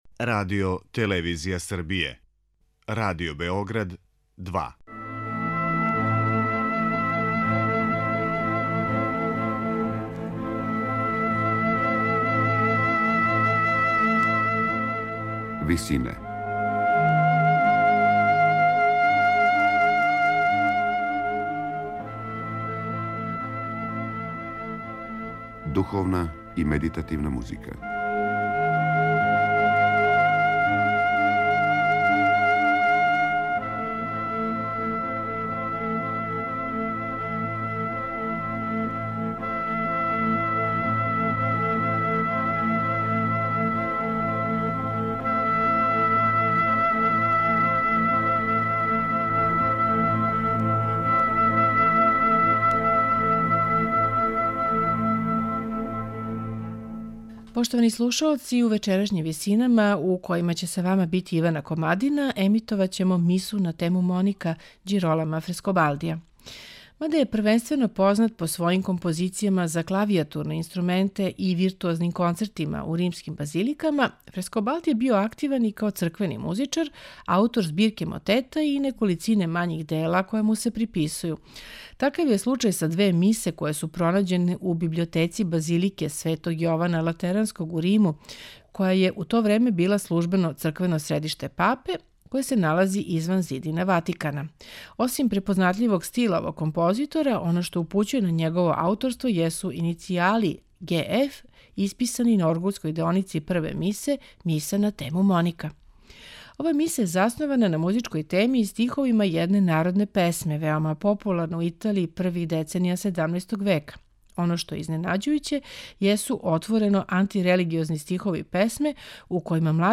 оргуљаш